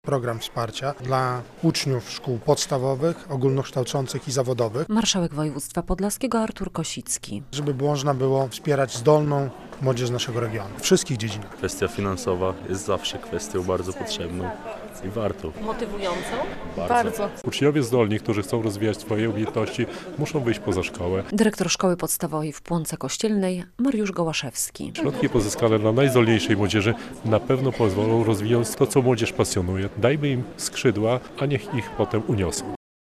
Przewidziane jest ono dla uczniów szkół podstawowych i ponadpodstawowych, z wysoką średnią ocen i dochodem, który na osobę w rodzinie nie przekracza 2022 złotych brutto miesięcznie, a w przypadku osoby z niepełnosprawnością - 2292 złotych brutto miesięcznie - mówi marszałek województwa podlaskiego Artur Kosicki.